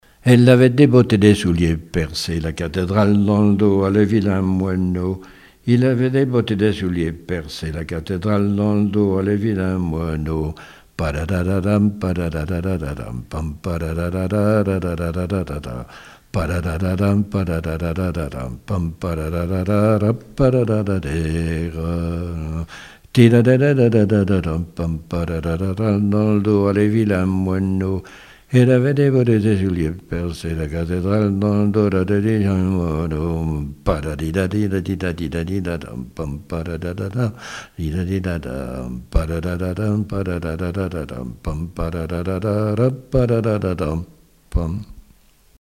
gestuel : danse
Témoignages et chansons
Pièce musicale inédite